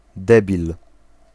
Ääntäminen
IPA: /ˈdɛbil/